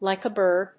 アクセントlìke a bírd